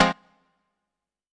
HOUSE126.wav